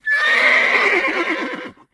c_whorse_hit2.wav